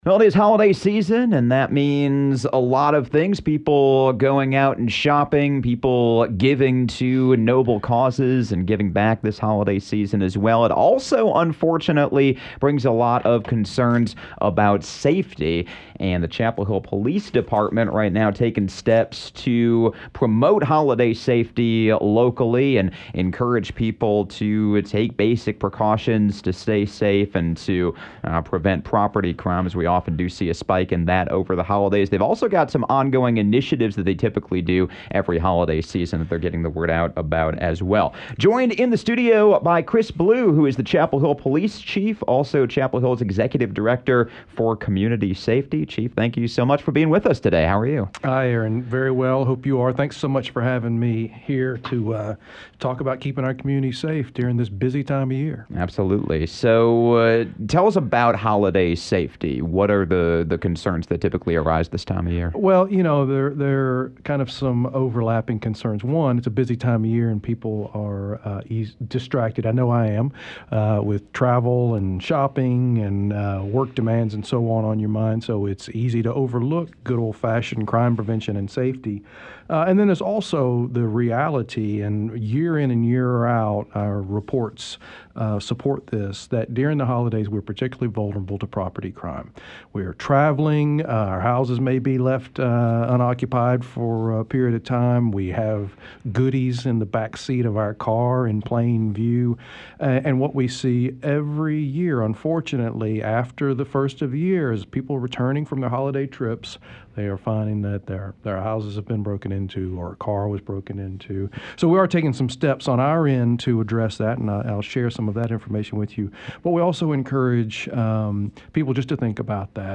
Chapel Hill Police Chief Chris Blue stops by with some holiday safety tips – including the CHPD’s “House Check” program, which is active all year round.